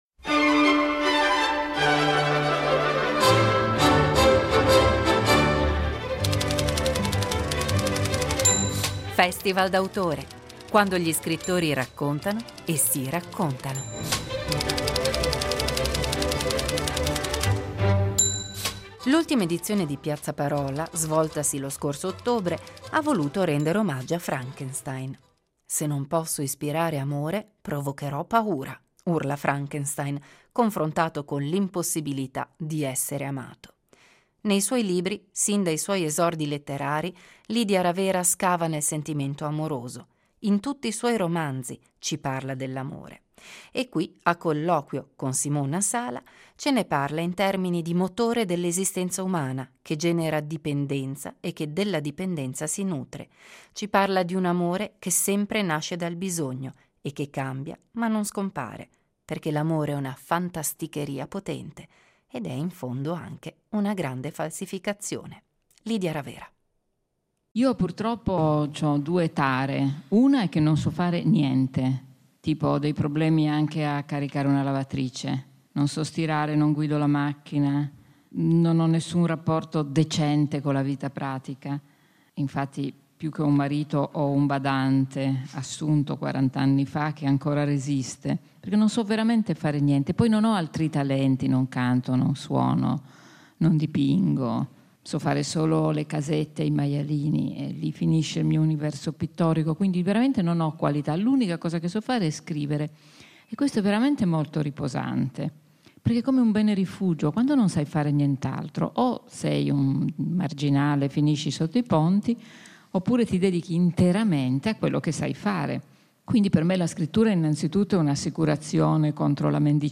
Il meglio dai Festival letterari della regione
L’ultima edizione di Piazzaparola, svoltasi a fine ottobre, ha voluto rendere omaggio a Frankenstein.